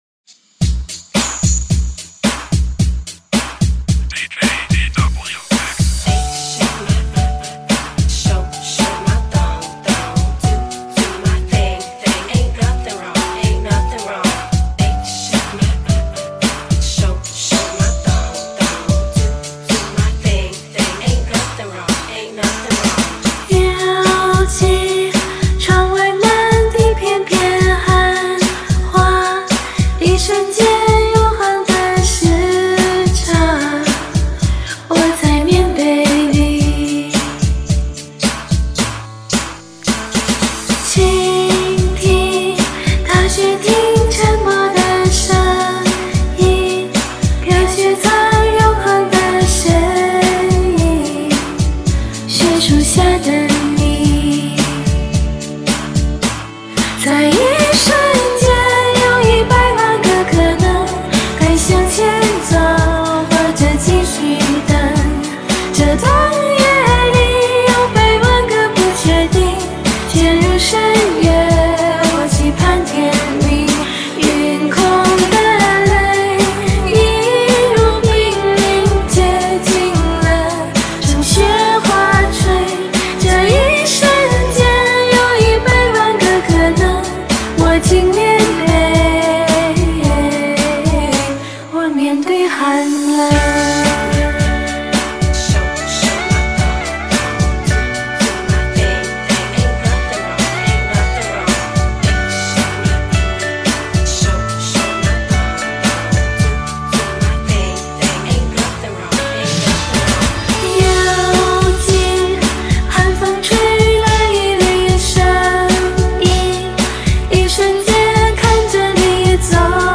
坐沙花听歌，慢摇的节奏，很悠闲
真带劲，听着要跟着蹦达起来了~~
年轻的声音，年轻的歌~~